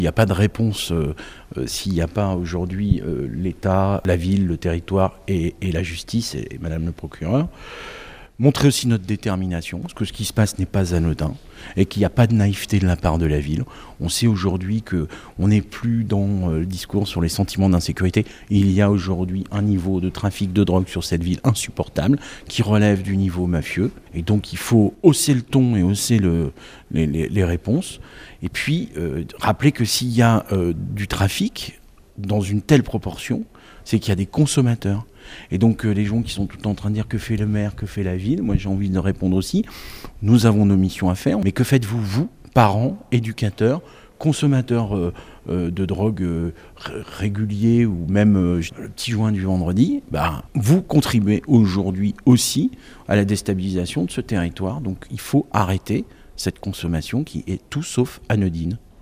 Il donnait ce mardi en préfecture une conférence de presse à trois voix avec le préfet du Puy-de-Dôme Philippe Chopin et la procureure de la République Dominique Puechmaille.
Réaction d’Olivier Bianchi, maire de Clermont-Ferrand, sur l’appel à agir collectivement :